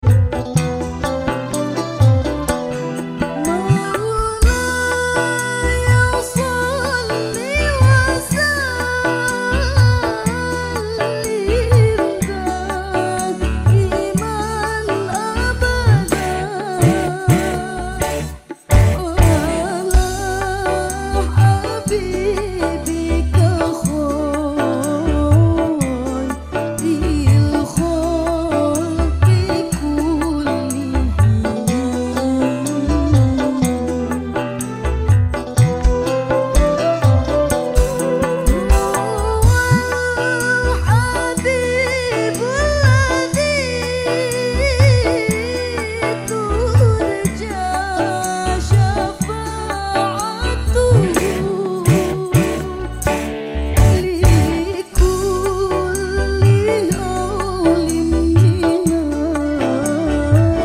Kenapa di dangdutin si 😭 kalo di dangdutin gjd sholawat 😔
wonge ayu suaranya merdu banget semoga selalu sehat amin ya rabbal alamin